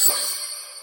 click_effect.mp3